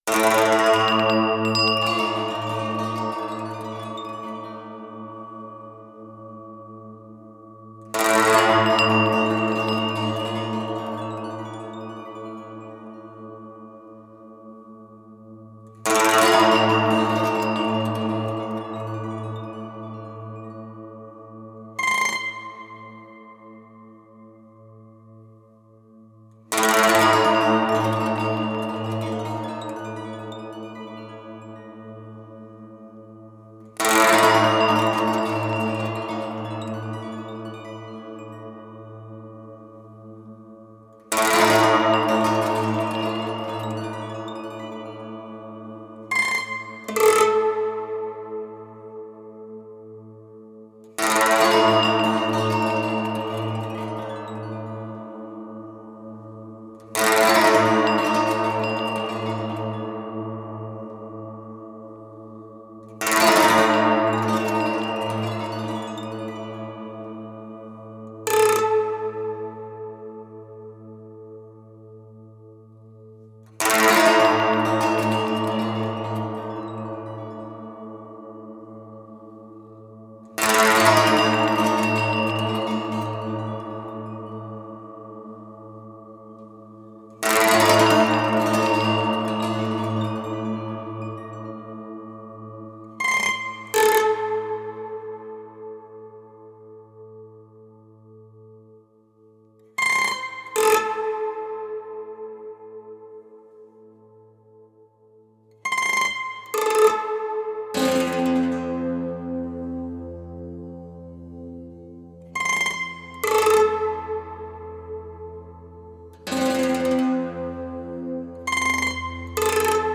ambient music project